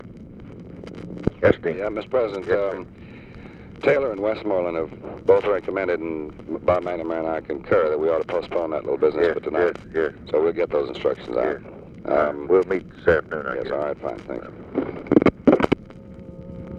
Conversation with DEAN RUSK, February 18, 1965
Secret White House Tapes